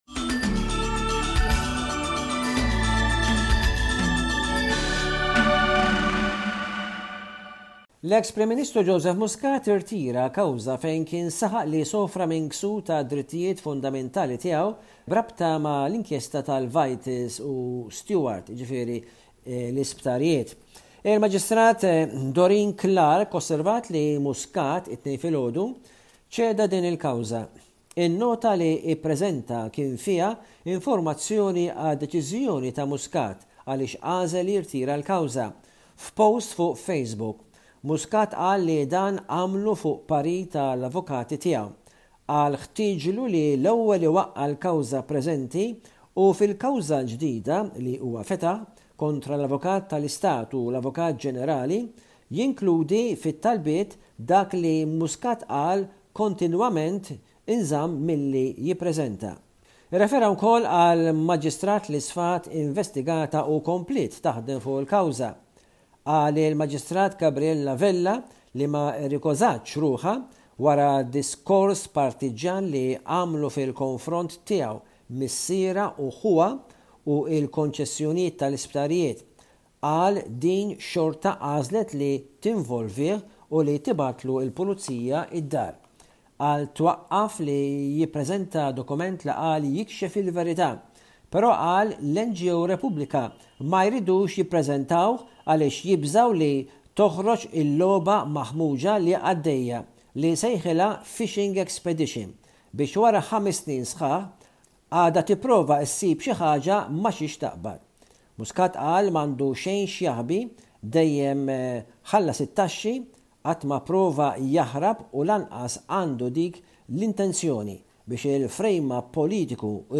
News report from Malta by SBS Radio correspondent